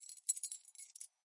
钥匙扣 " 钥匙扣15
描述：录音设备：Sony PCMM10Format：24 bit / 44.1 KHz
Tag: 样品 记录 弗利